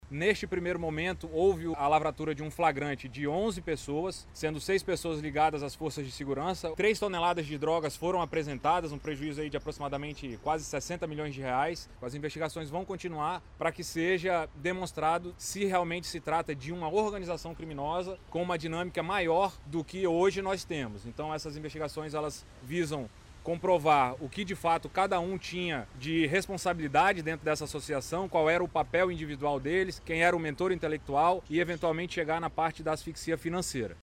O delegado-geral da Polícia Civil, Bruno Fraga, destacou o prejuízo financeiro estimado com a apreensão.